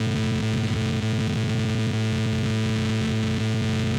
Sci-Fi Sounds / Hum and Ambience / Hum Loop 1.wav
Hum Loop 1.wav